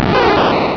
bulbasaur.wav